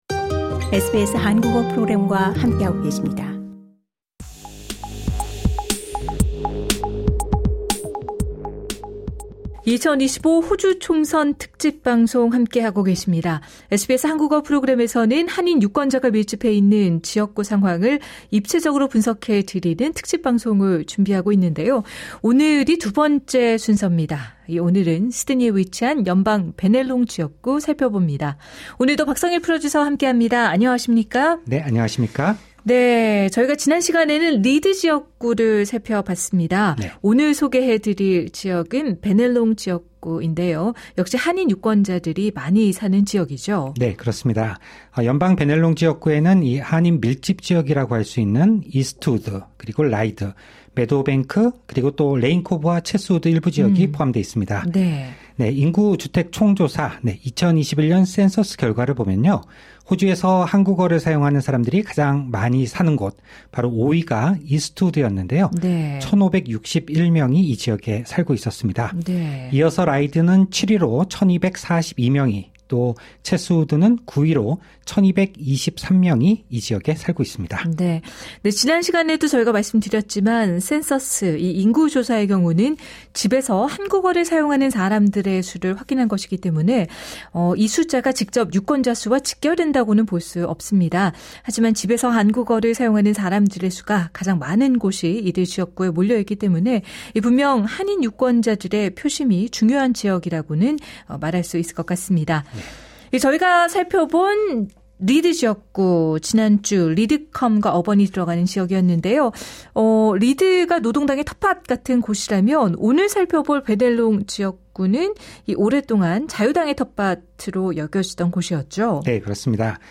이스트우드·라이드 포함 SBS Korean 10:32 Korean SBS 한국어 프로그램에서는 한인 유권자가 밀집해 있는 지역구의 상황을 입체적으로 분석해 드리는 특집 방송을 준비했습니다.